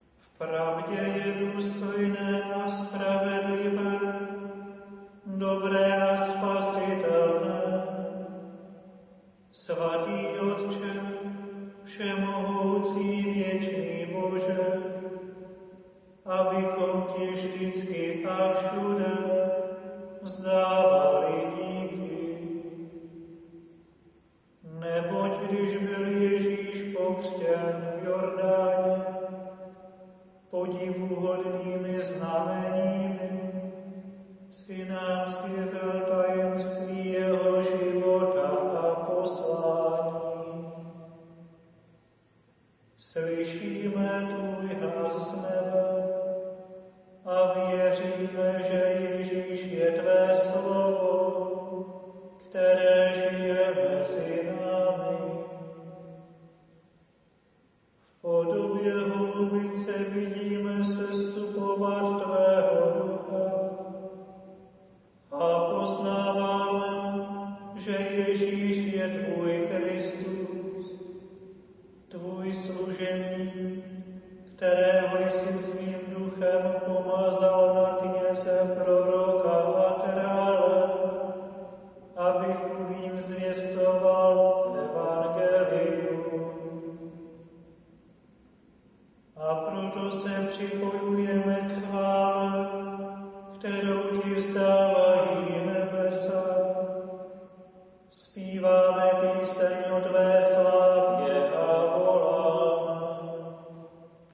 Notovaná verze: chorál (noty,